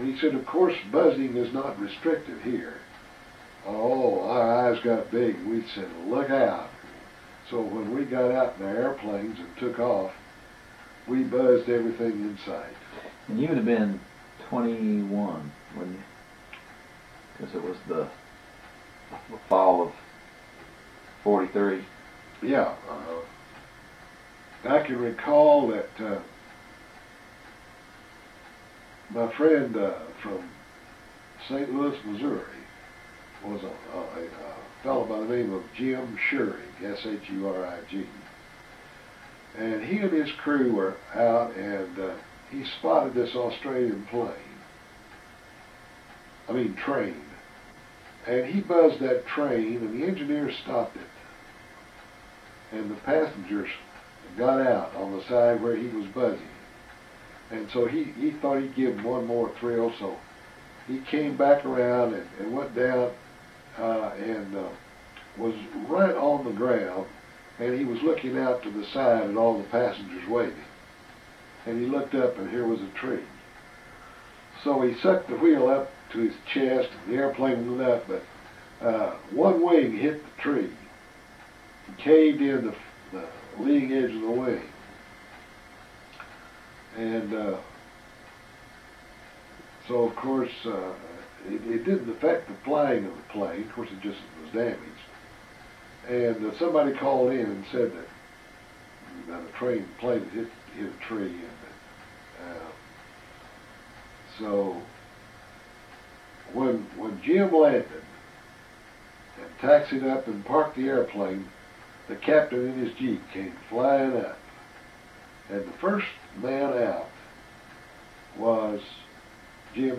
and interview with his father.